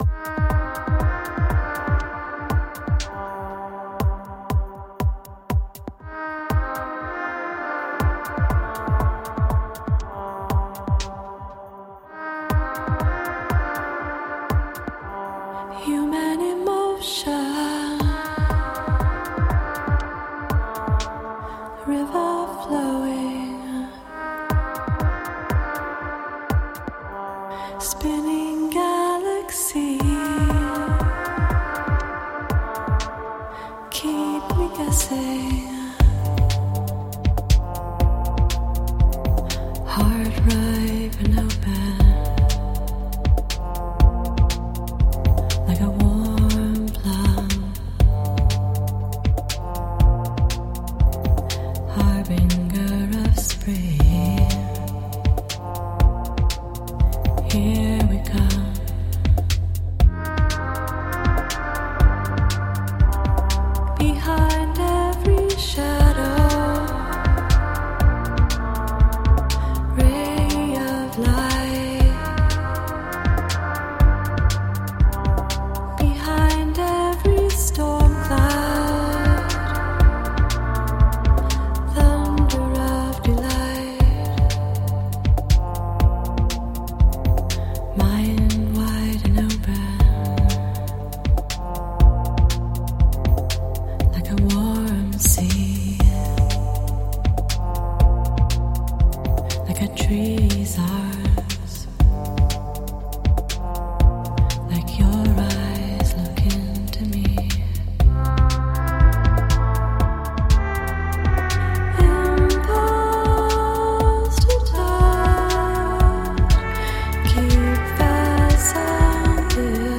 Alternative / downtempo / electro-pop.